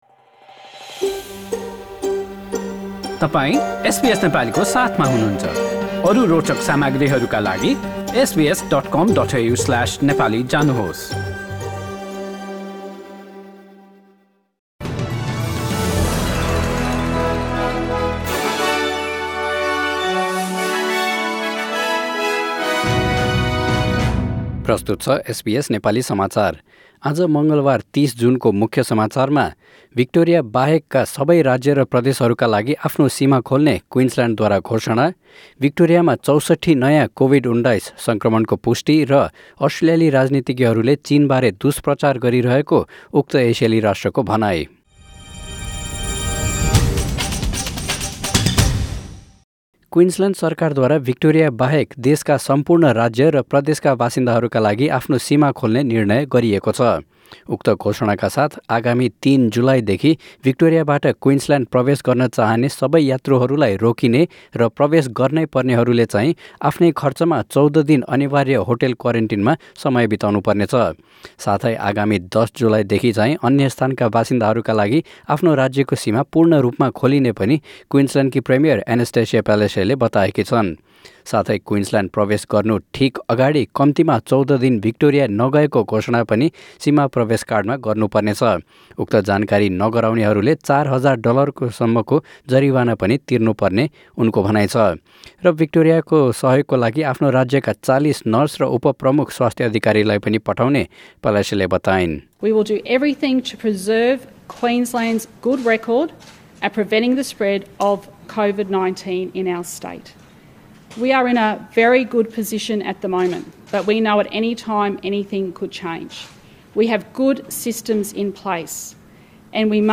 Listen to the latest news headlines in Australia from SBS Nepali radio.